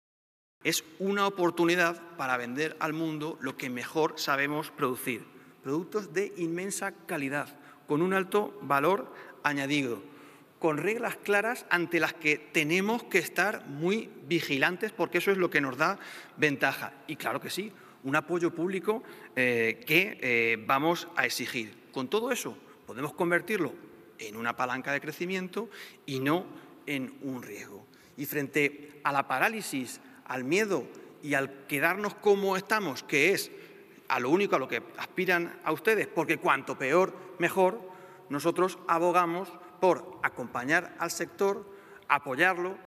En su intervención en el pleno del Parlamento autonómico durante el debate